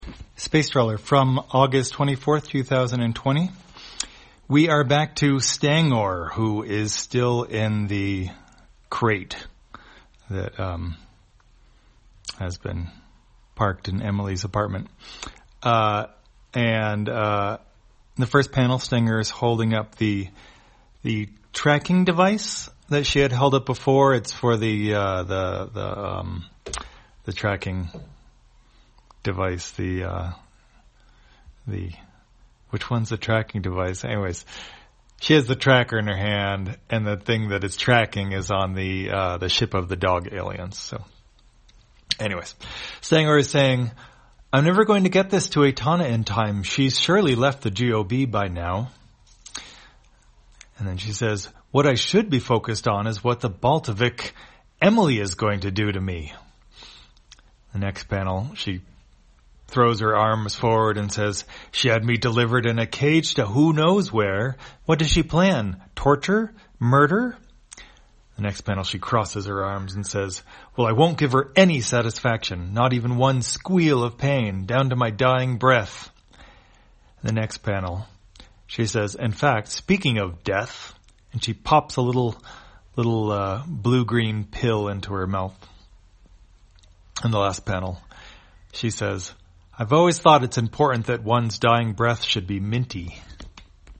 Spacetrawler, audio version For the blind or visually impaired, August 24, 2020.